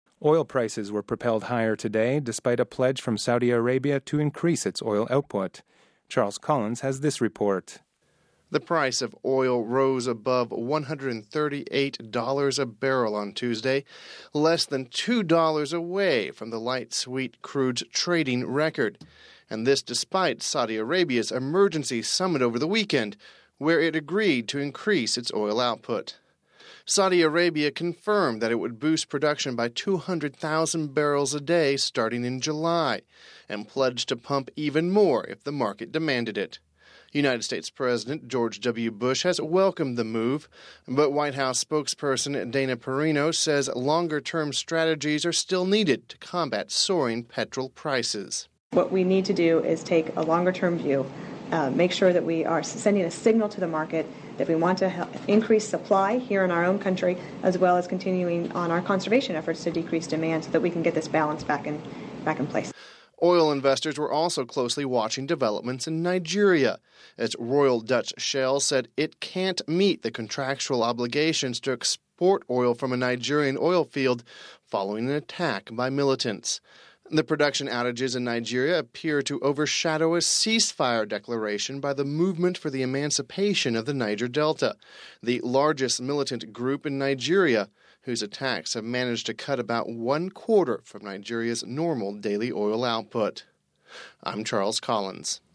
Home Archivio 2008-06-24 18:36:33 Oil Prices Propelled Higher (24 June 08 - RV) Oil prices were propelled higher ton Tuesday despite a pledge from Saudi Arabia to increase its oil output. We have this report...